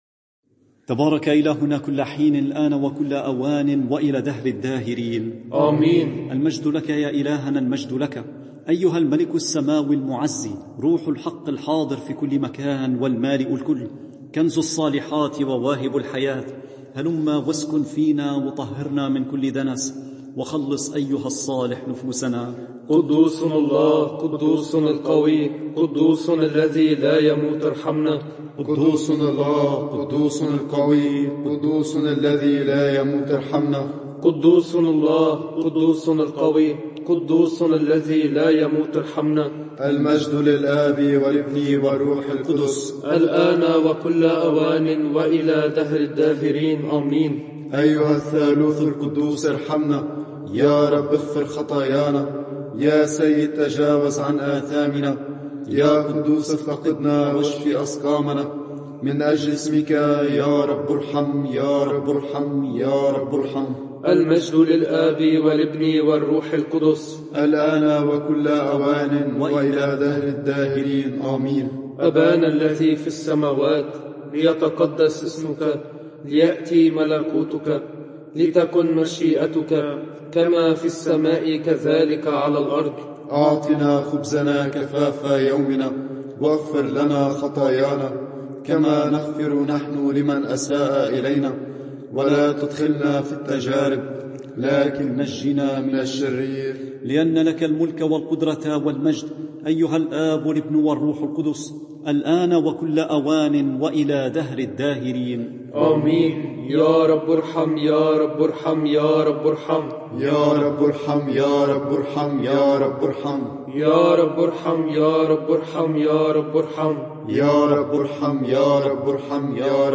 صلاة الساعة التاسعة رهبان
صلاة الساعة التاسعة رهبان.mp3